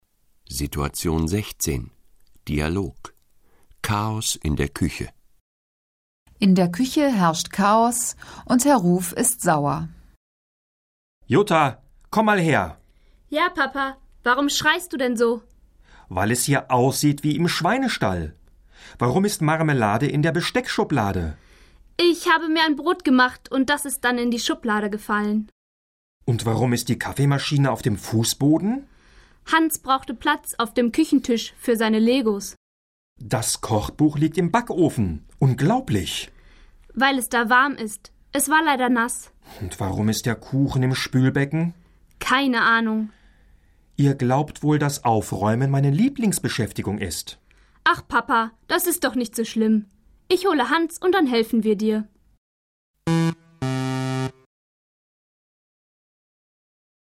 Situation 16 – Dialog: Chaos in der Küche (917.0K)